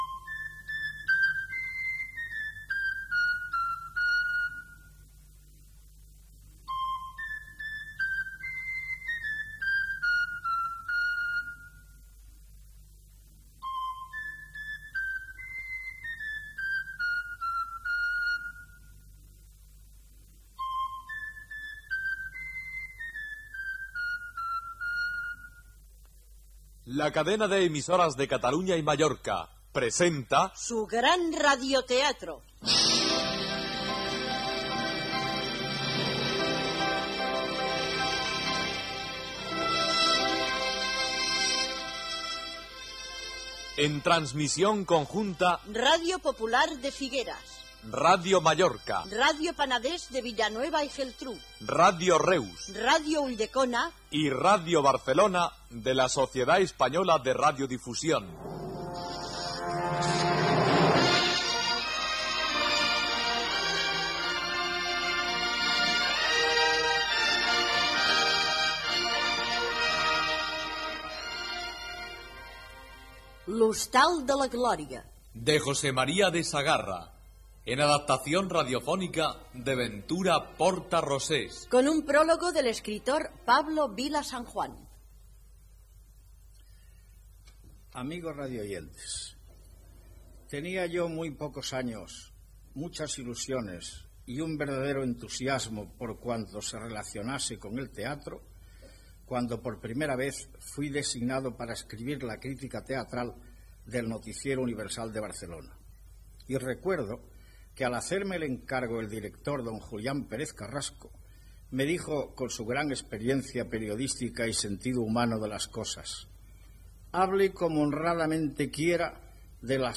Introducció del lloc i moment en el qual passa l'obra i primeres escenes. Gènere radiofònic Ficció